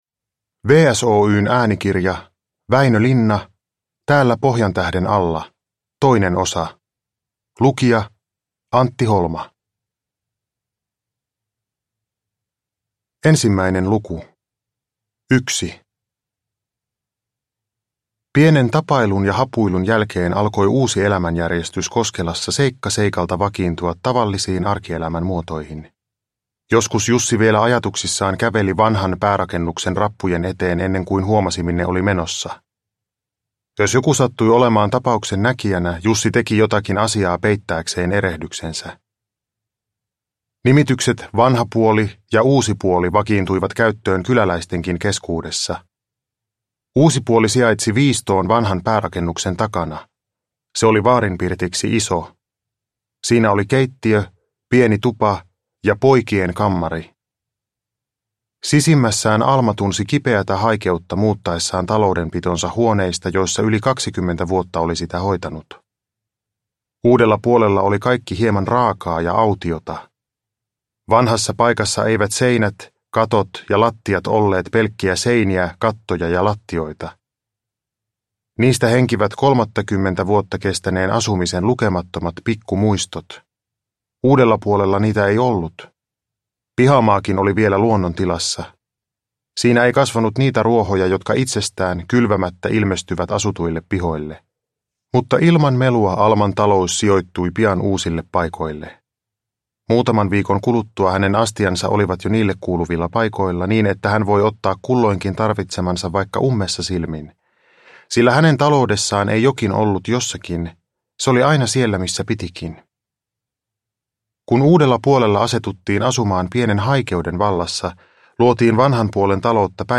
Uppläsare: Antti Holma